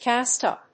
cást úp